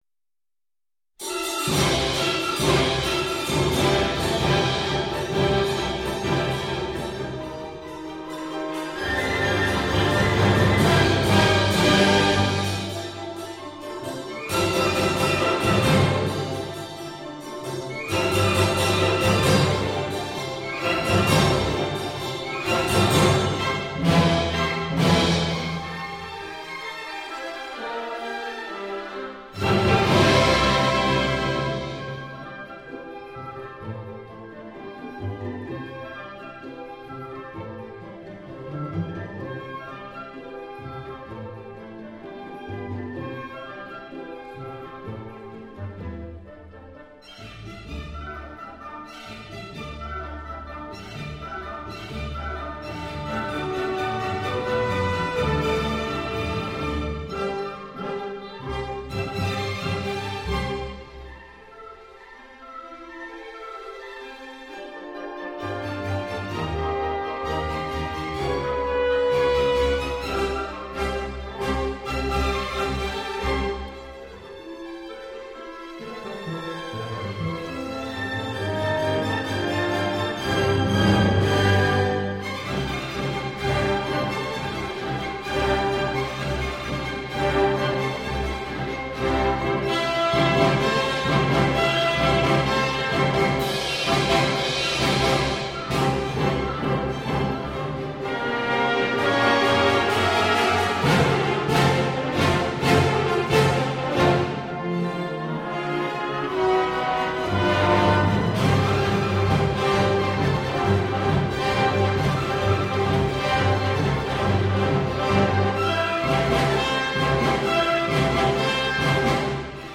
★琅琅上口的輕鬆吉祥小調讓您喜上加喜